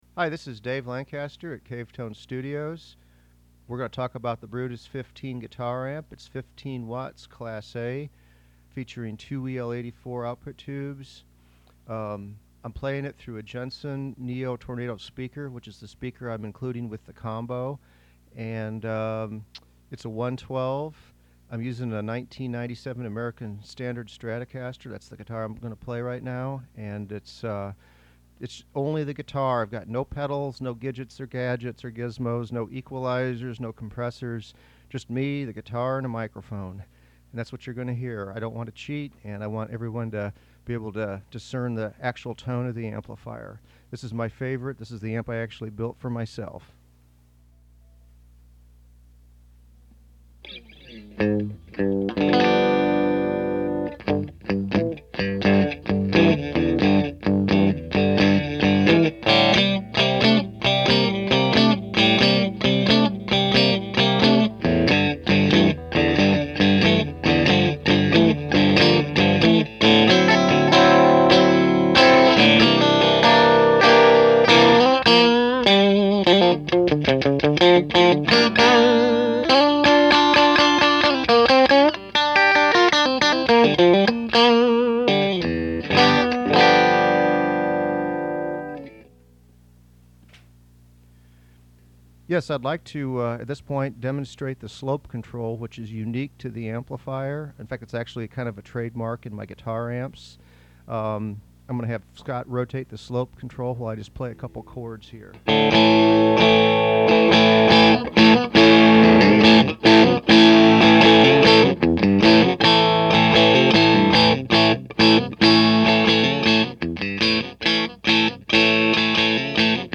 Brutis 15 Tube Guitar Amplifier
The sounds it makes: The Brutis recreates vintage Vox, Fender, and Marshall tones of the late 50s and 60s. Due to the amplifier's slope control, it can sound tight like a vintage AC30. When rotated in the other direction, it creates the smoothness and grind of a '59 Bassman.